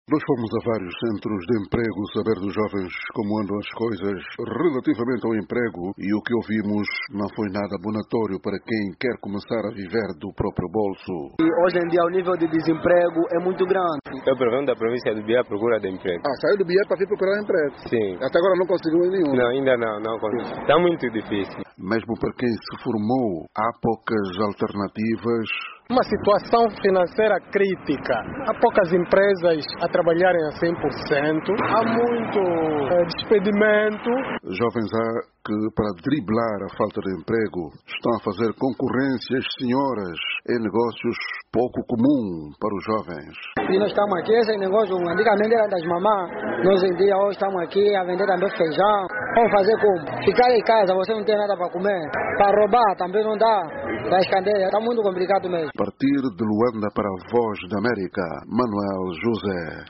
A VOA foi até aos centros de empregos e o que ouvimos não é nada abonatório para quem quer começar a viver do próprio bolso.